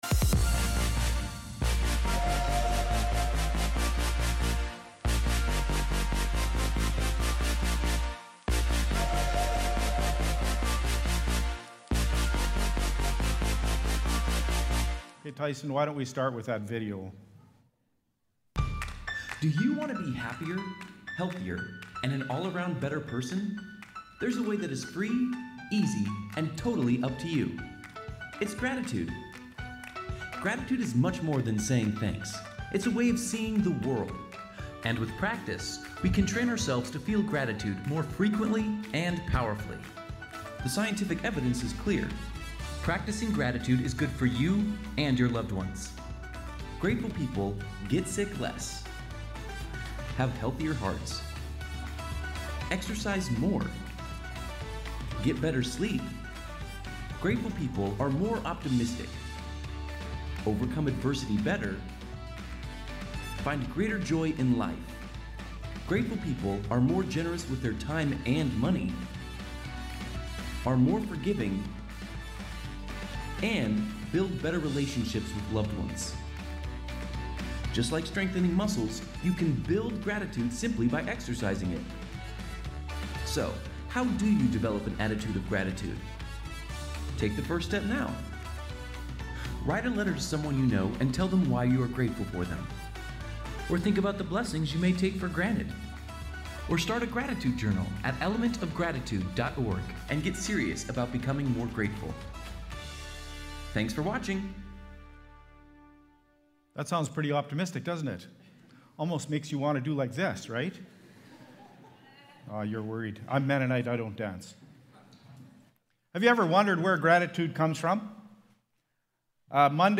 Message
October-6-Worship-Service.mp3